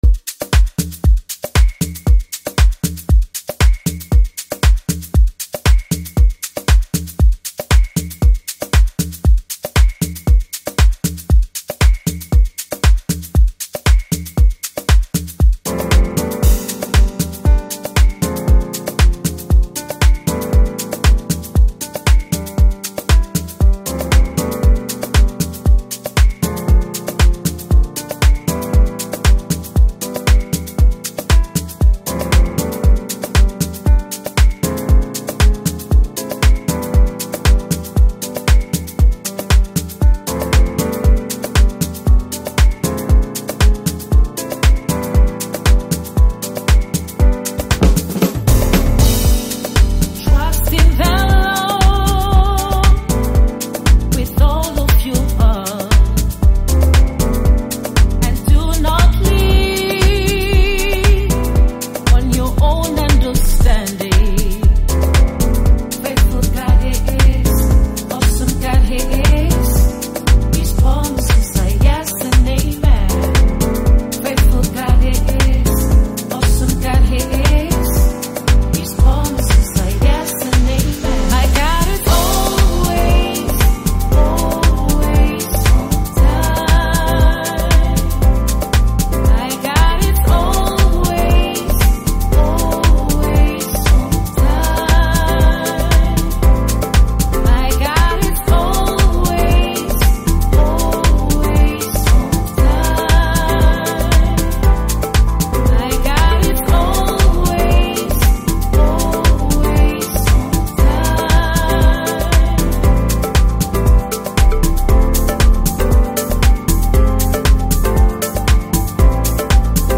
Home » Amapiano » DJ Mix » Hip Hop
South African singer-songwriter